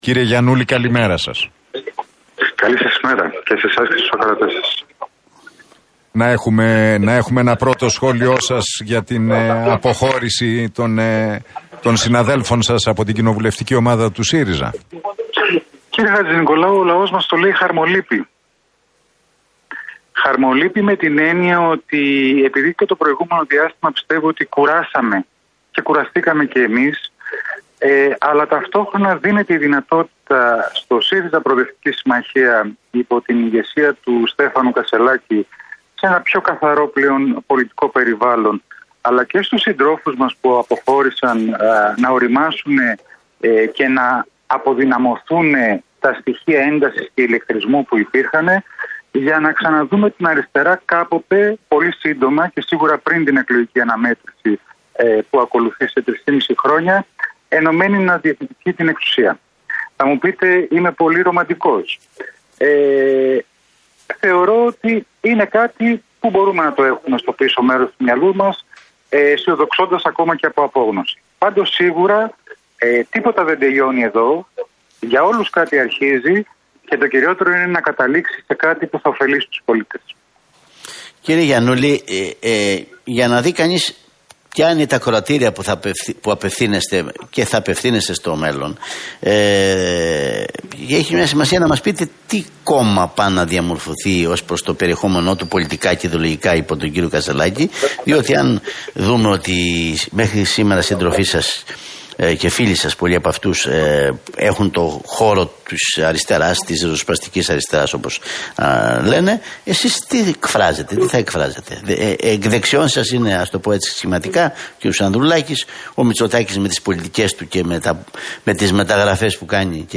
Γιαννούλης στον Realfm 97,8: Χαρμολύπη για την αποχώρηση των 9 συντρόφων - Πιο καθαρό πολιτικό περιβάλλον στον ΣΥΡΙΖΑ υπό την ηγεσία του Στέφανου Κασσελάκη